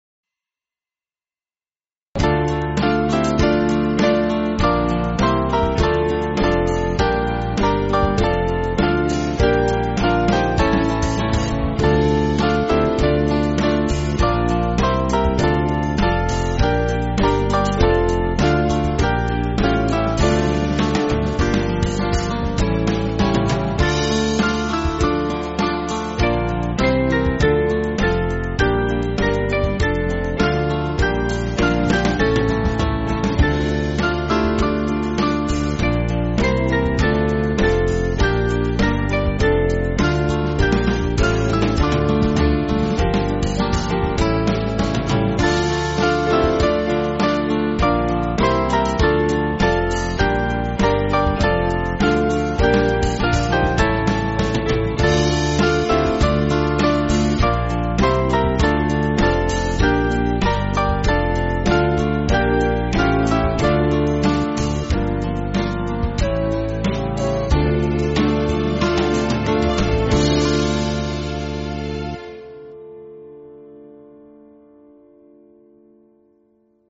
Small Band
(CM)   3/Dm